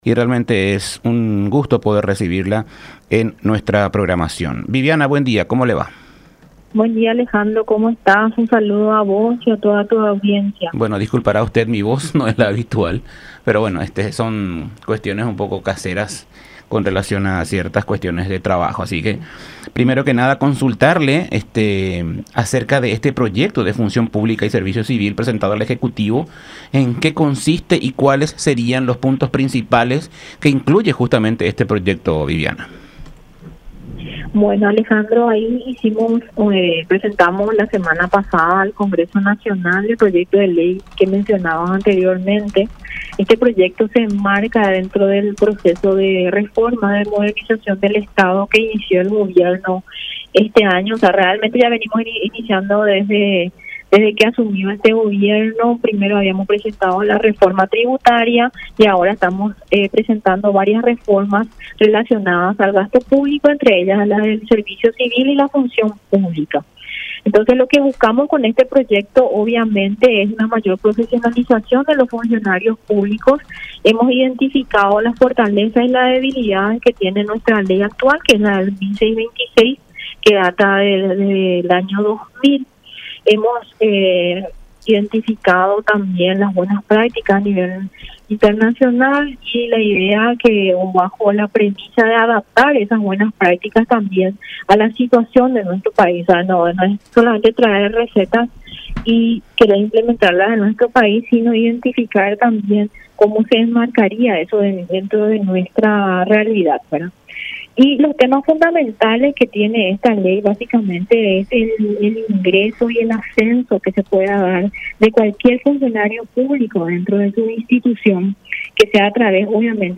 en diálogo con La Unión R800 AM